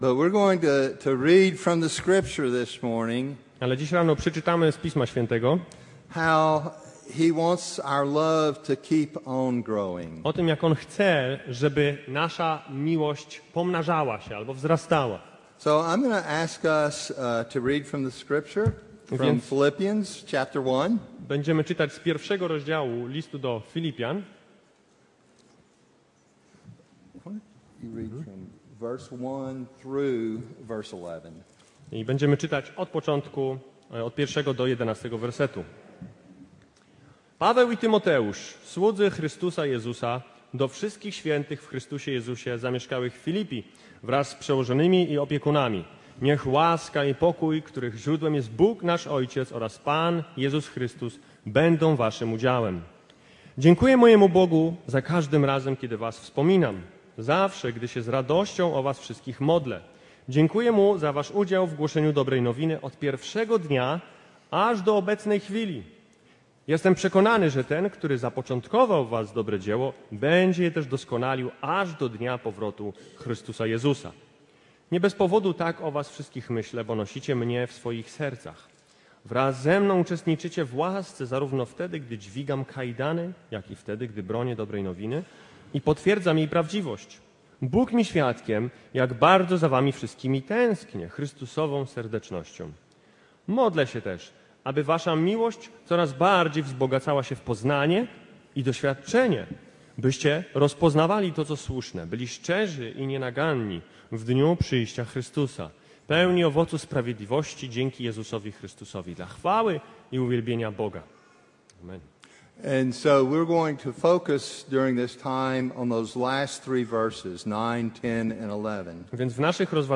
Passage: List do Filipian 1, 1-11 Kazanie